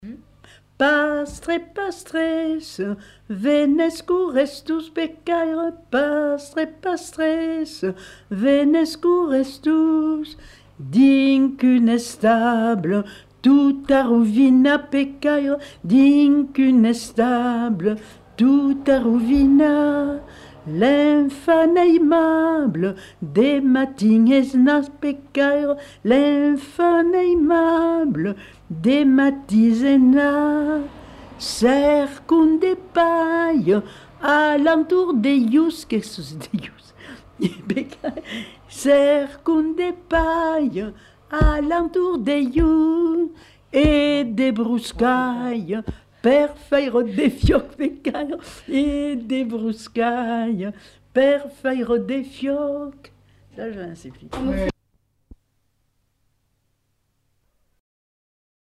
Lieu : Arrigas
Genre : chant
Effectif : 1
Type de voix : voix de femme
Production du son : chanté
Classification : noël